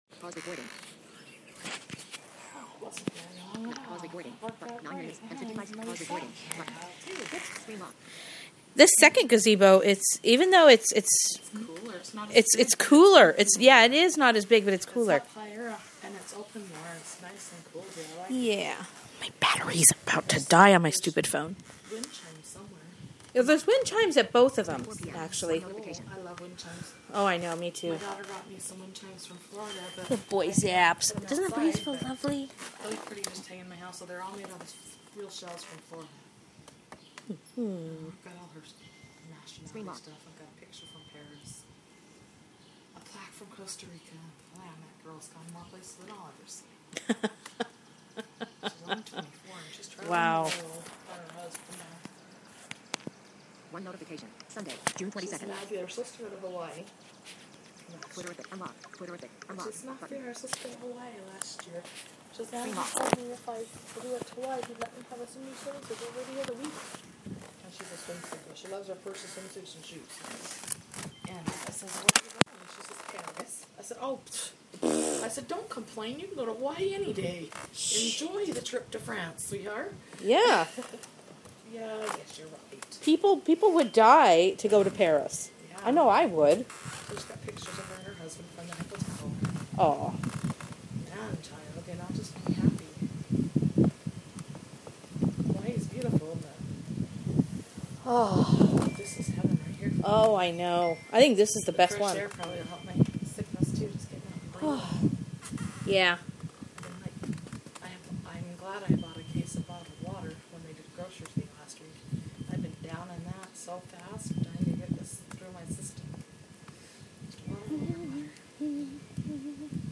A continuation of my last recording, except for the battery dying halfway through.